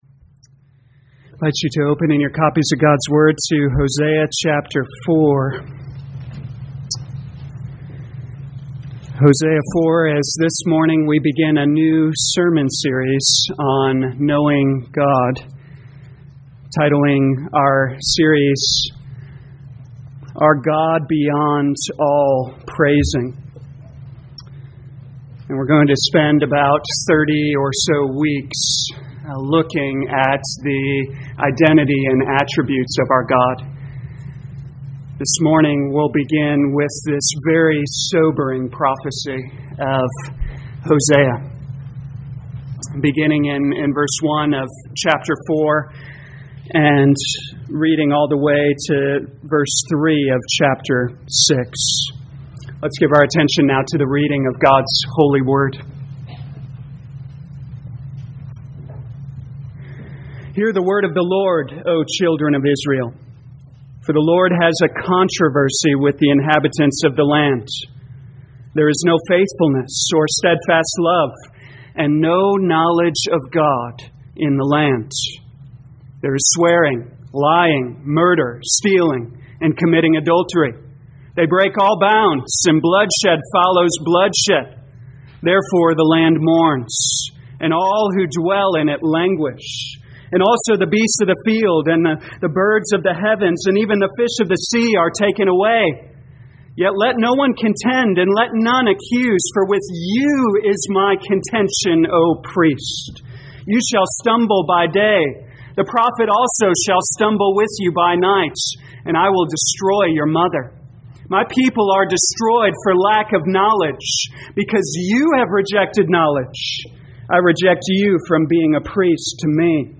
2022 Hosea Knowing God Morning Service Download